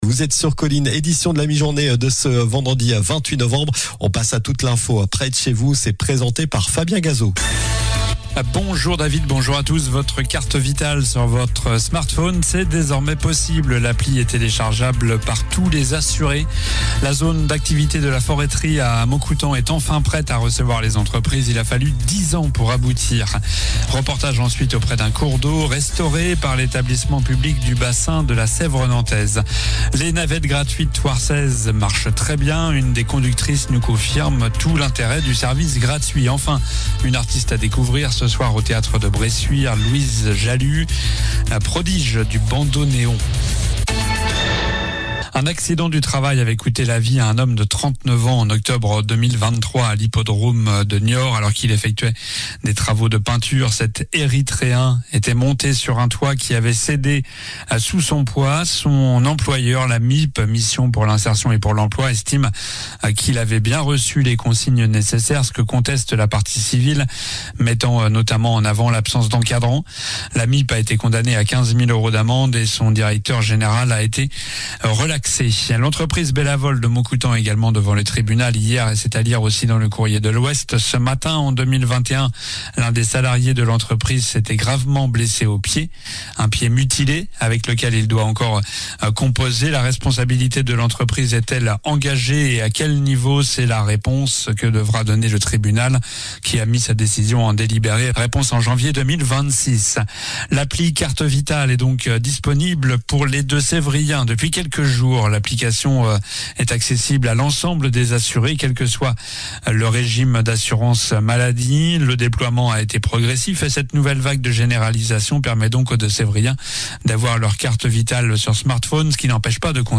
Journal du vendredi 28 novembre (midi)
Votre carte vitale sur votre smatphone c'est désormais possible - La zone d'activités de la Foresterie à Moncoutant est enfin prête - Reportage auprès d'un cours d'eau restauré par l'Etablissement public du bassin de la Sèvre nantaise (photo)